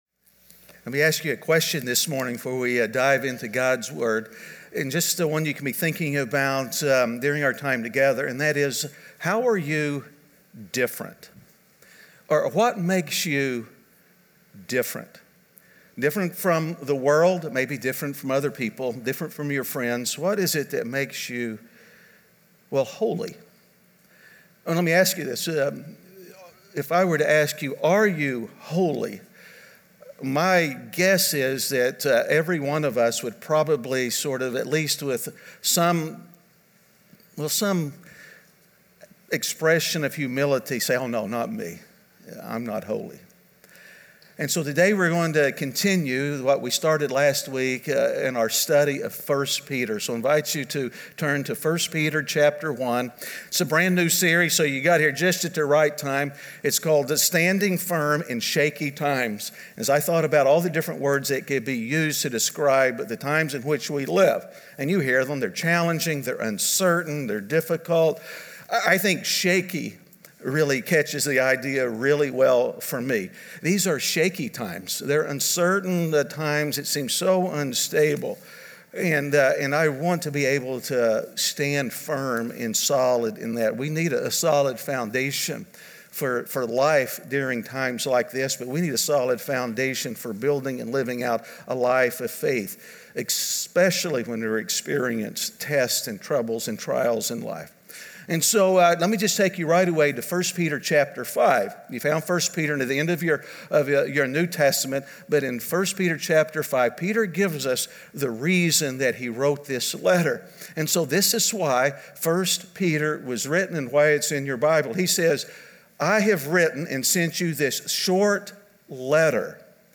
Standing Firm In Shaky Times (Week 2) - Sermon.mp3